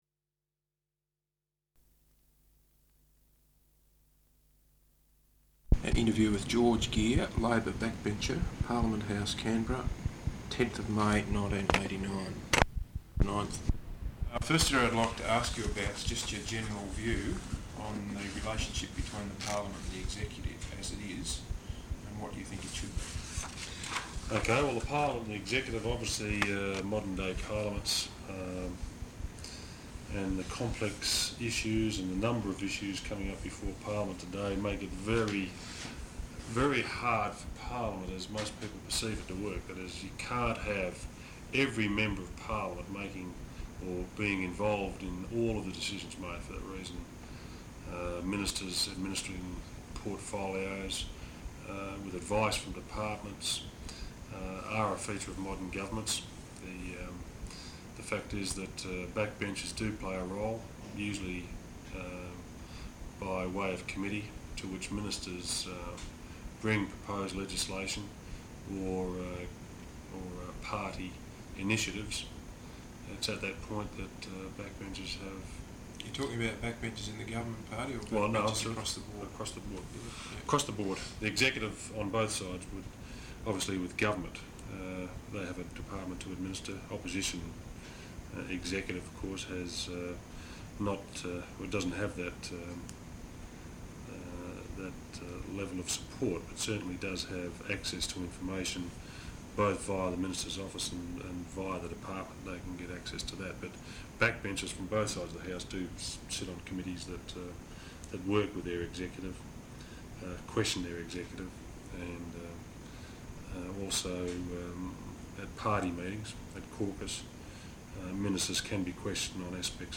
Interview with George Gear, Labor Backbencher, Parliament House, Canberra 10th May, 1989.